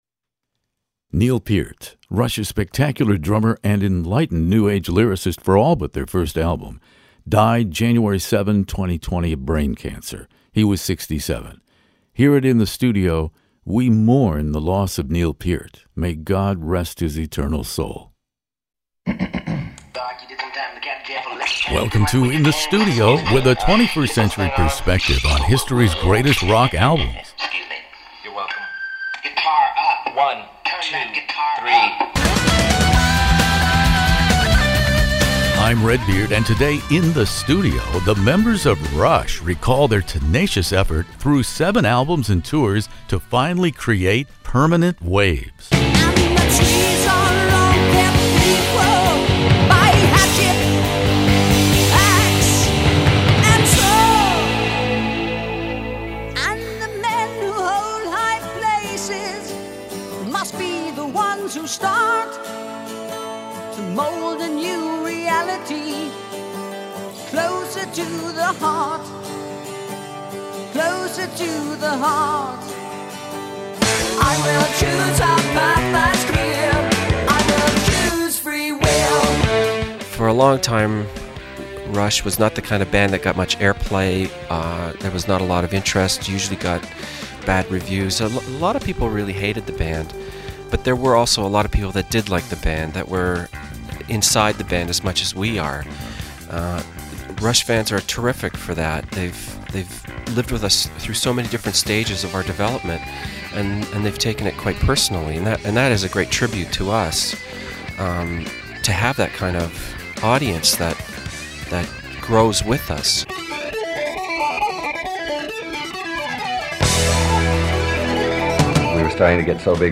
Rush Permant Waves 45th anniversary interview In the Stud
Geddy Lee, Alex Lifeson, and archival comments from the late Neil Peart are all part of the Permanent Waves forty-fifth anniversary.